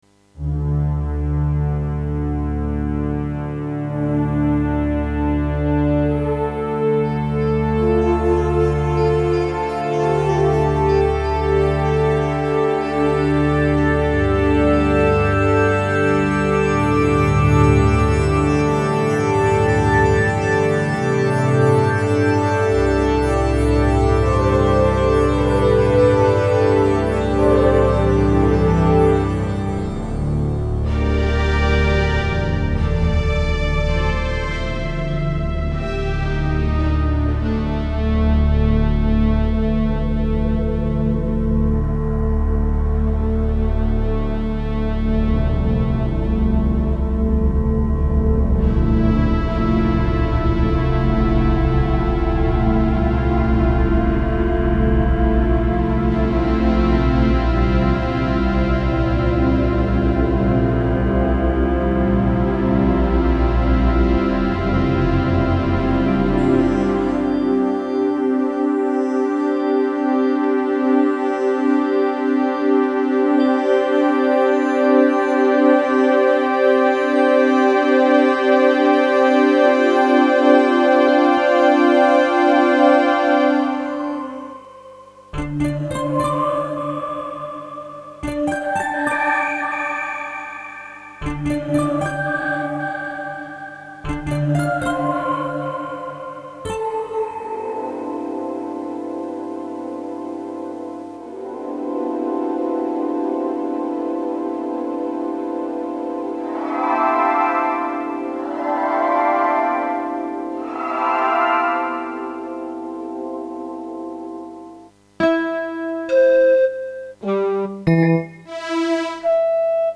The Wavestation uses some weird wave sequencing vector synthesis method (just like the Yamaha TG33 but more advanced I think, It is also designed by the same people who made the Sequential Circuits Prophet VS).
In theory the wavestation makes extremely evolving complex sounds ideal for soundtracks & ambient. The sound character however is a bit cold imo, it sounds very very 90s.
wavestation.mp3